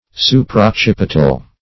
\Su`per*oc*cip"i*tal\